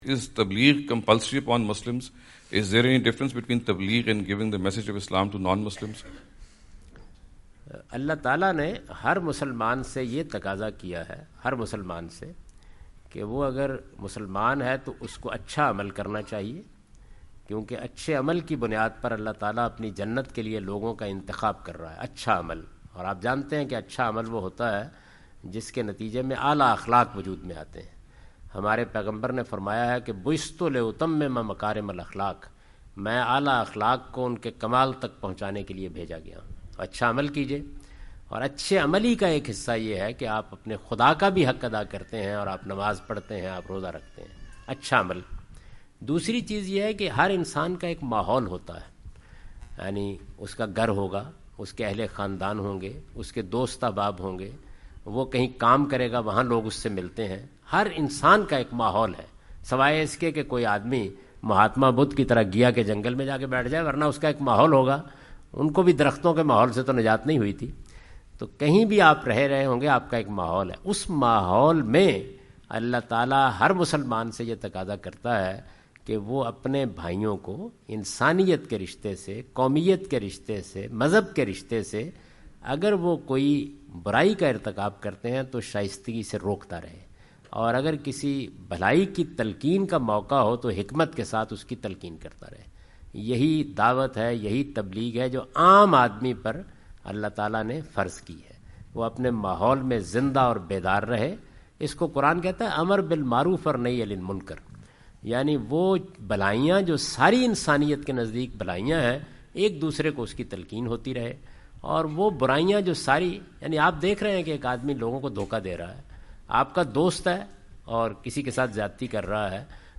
Javed Ahmad Ghamidi answer the question about "Is Preaching Obligatory for All Muslims?" during his US visit.
جاوید احمد غامدی اپنے دورہ امریکہ کے دوران ڈیلس۔ ٹیکساس میں "کیا دین کی تبلیغ تمام مسلمانوں پر لازم ہے؟" سے متعلق ایک سوال کا جواب دے رہے ہیں۔